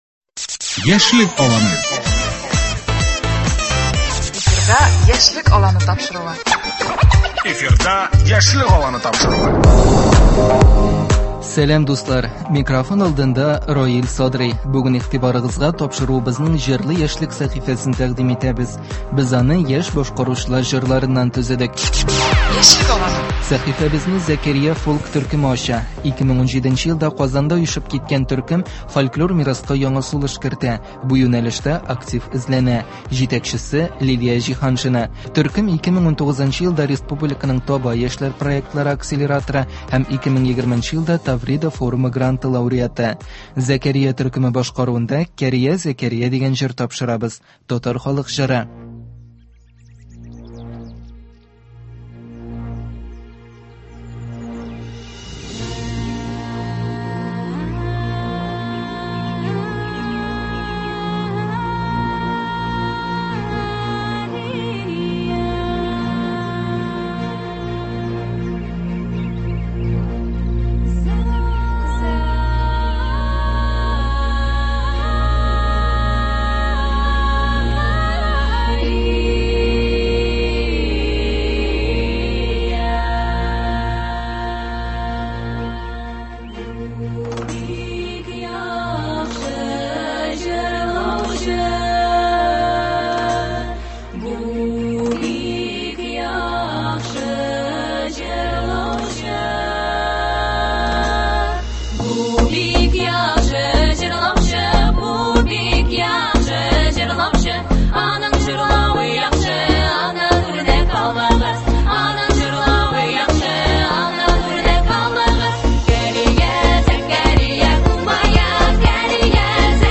Яшь башкаручылар чыгышы.